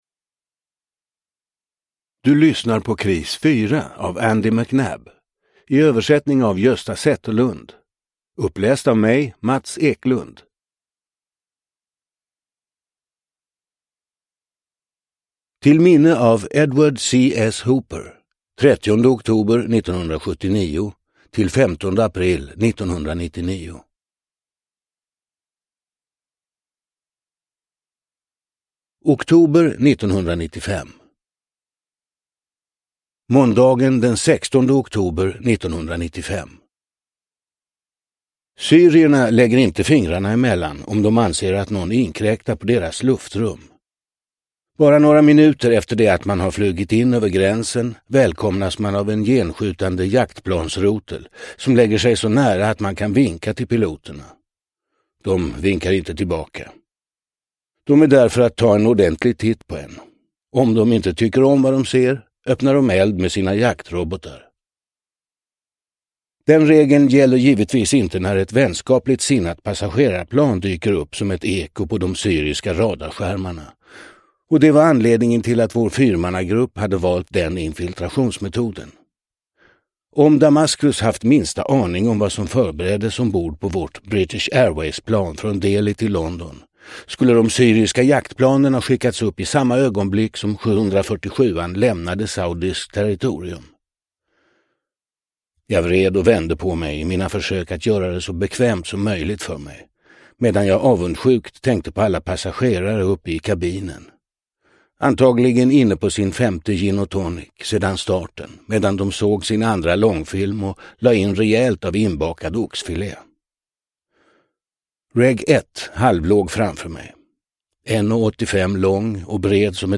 Kris fyra – Ljudbok – Laddas ner